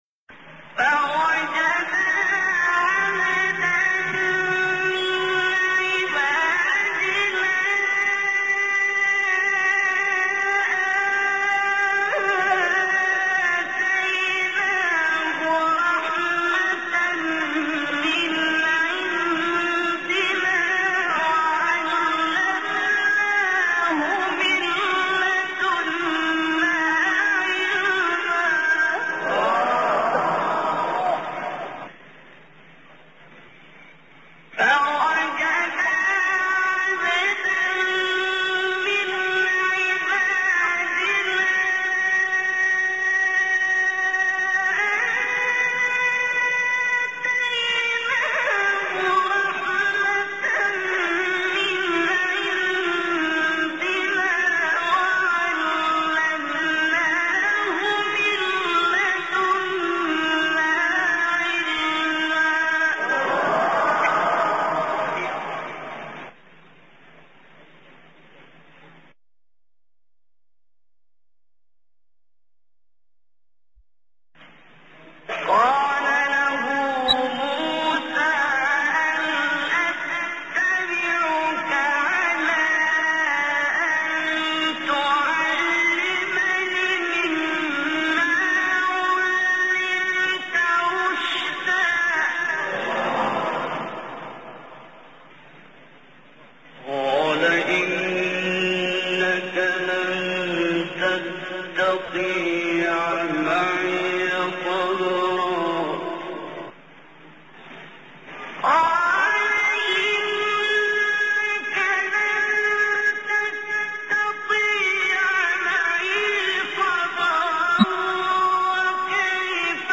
قراءة لعبد الباسط عبد الصمد لن تسمع مثل روعتها في حياتك
آآآآه ، يا لعظمة هذا الصوت .. !
آآه ، صوت عظيم !